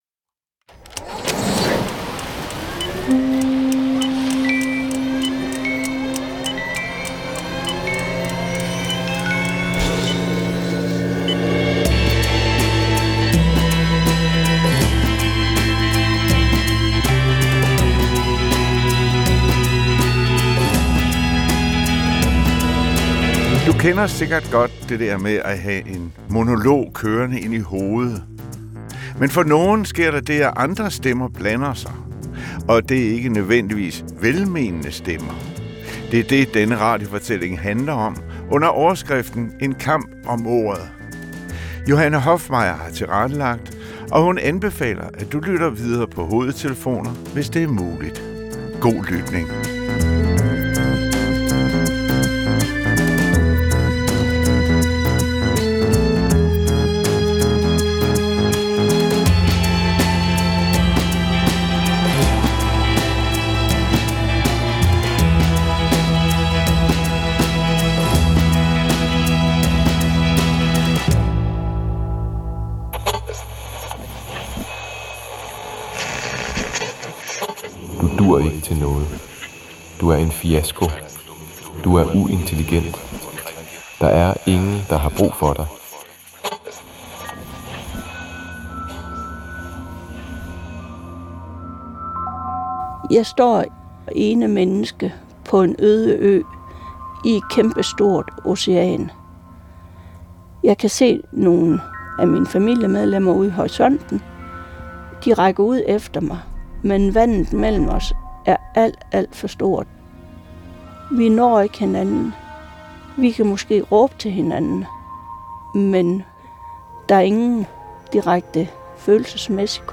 … continue reading 146 episoder # Historiefortælling # Radiodrama # Samfund # Dokumentarfilm